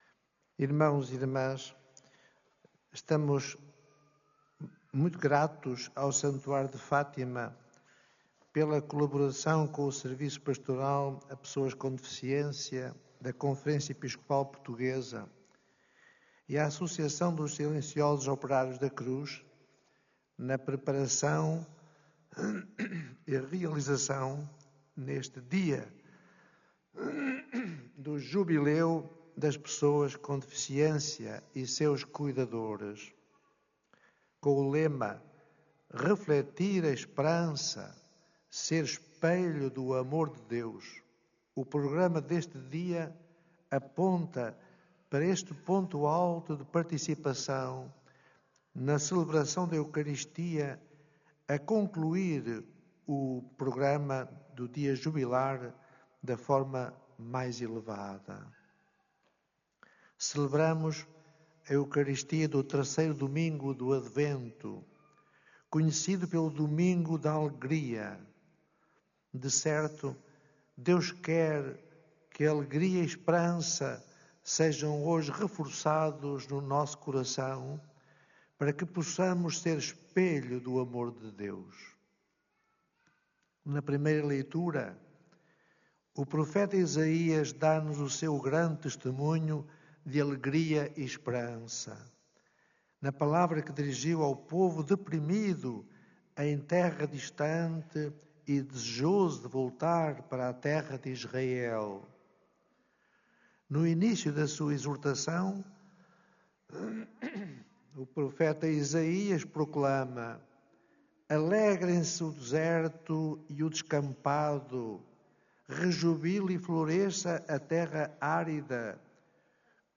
O programa do Jubileu culminou com a celebração da Eucaristia.
Áudio da homilia de D. José Traquina